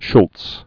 (shlts), Charles Monroe 1922-2000.